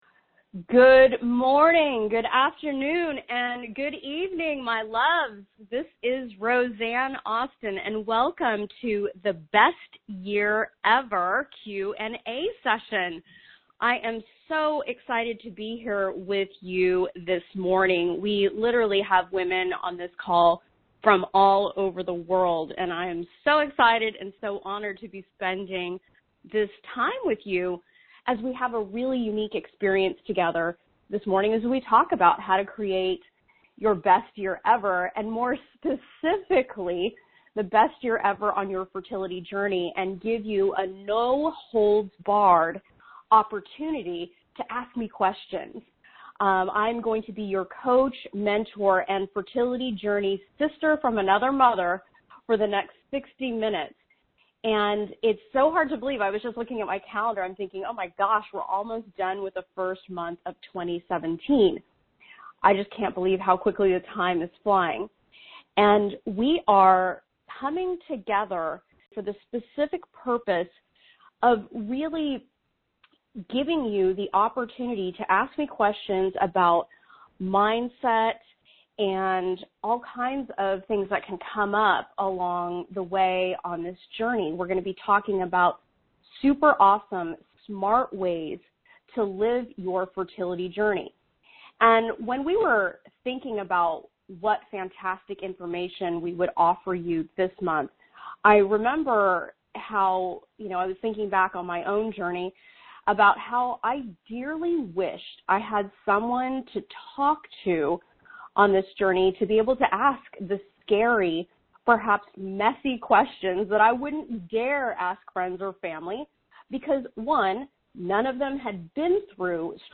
Best+Year+Ever+Q&A+C+BEd.mp3